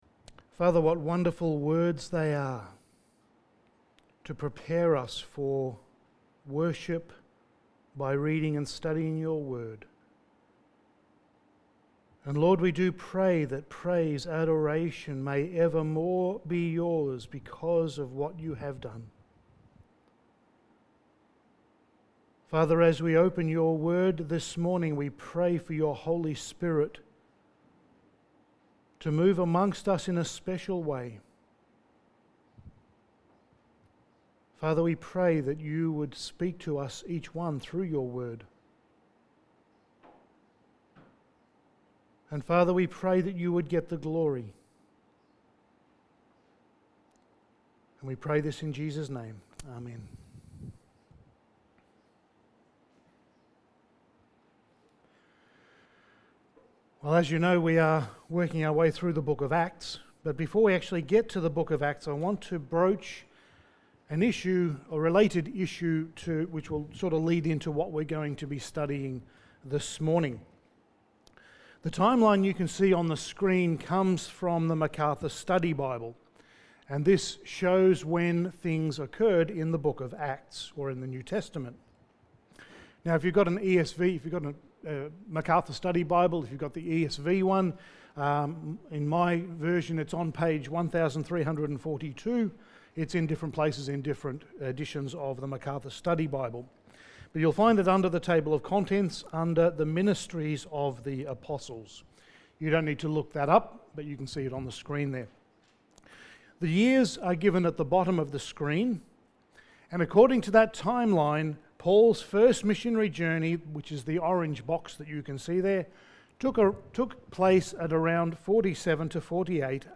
Passage: Acts 15:22-41 Service Type: Sunday Morning